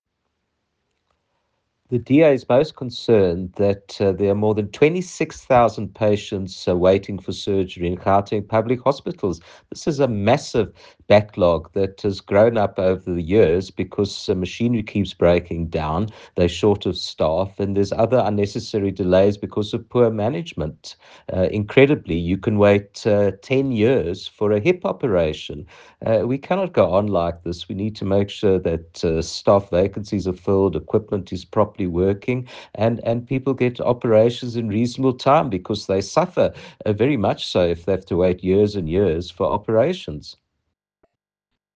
Note Editors: Attached please find a soundbite in English by Dr Jack Bloom MPL